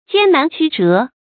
艱難曲折 注音： ㄐㄧㄢ ㄣㄢˊ ㄑㄩ ㄓㄜˊ 讀音讀法： 意思解釋： 困難和曲折 出處典故： 毛澤東《關于正確處理人民內部矛盾的問題》：「任何新生事物的成長都是要經過 艱難曲折 的。